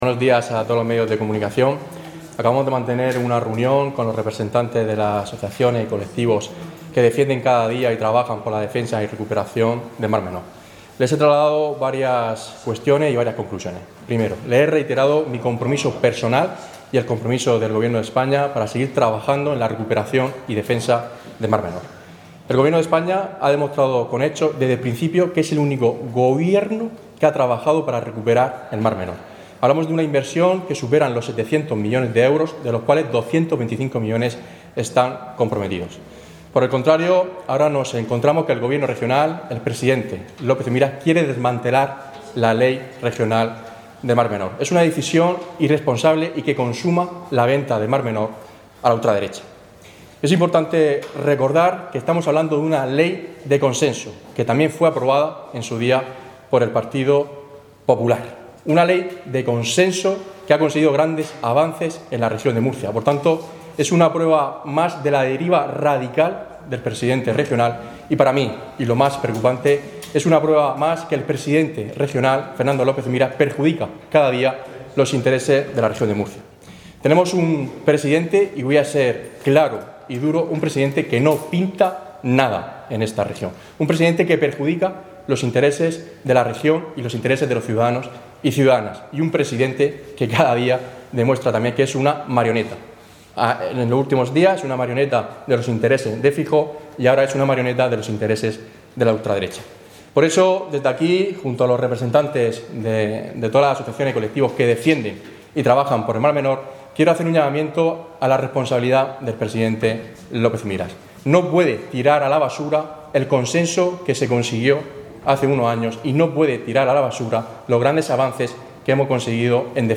Declaraciones de Francisco Lucas
Francisco Lucas, delegado del Gobierno